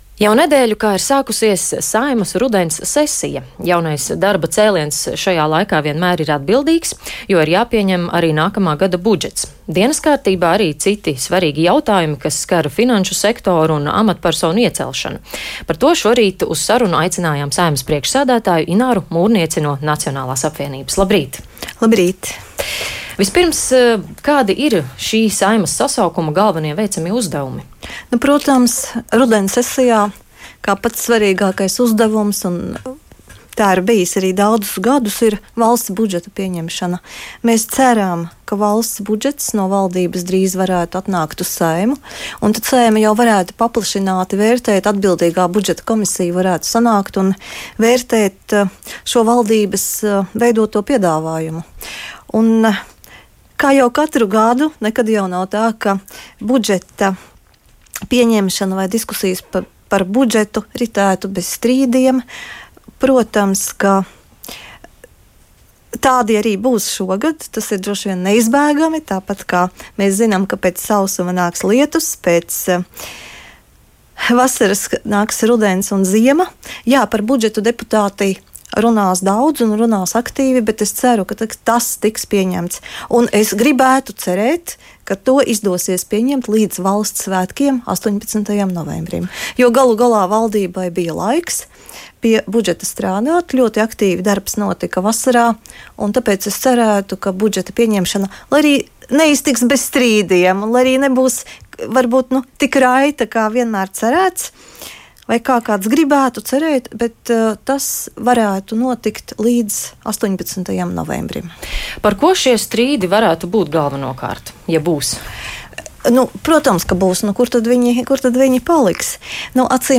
Saeimas rudens sesijā viens no svarīgākajiem darbiem ir valsts budžeta pieņemšana, intervijā Latvijas Radio norādīja Saeimas priekšsēdētāja Ināra Mūrniece (Nacionālā apvienība). Viņa prognozēja, ka arī šogad budžeta pieņemšana neiztiks bez strīdiem, taču cer, ka to izdosies pieņemt līdz valsts svētkiem 18. novembrī.